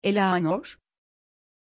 Aussprache von Éleanor
pronunciation-éleanor-tr.mp3